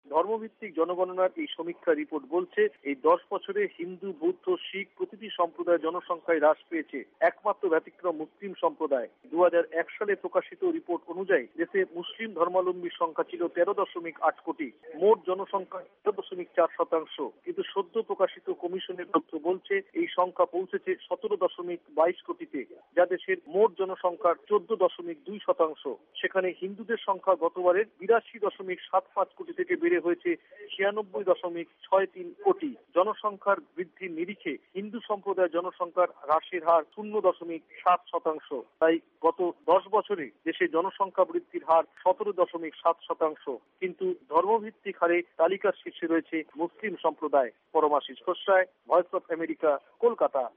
রেজিস্টার জেনারেল এবং সেনসাস কমিশনের সদ্য প্রকাশিত সমীক্ষায় এই তথ্য উঠে এসেছে। শুনুন কোলকাতায় আমাদের সংবাদদাতা